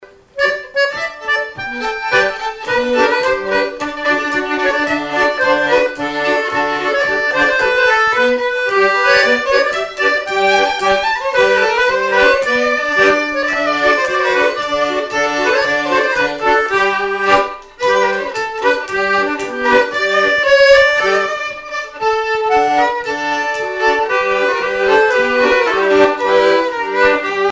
danse : marche
violon
Pièce musicale inédite